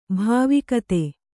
♪ bhāvikate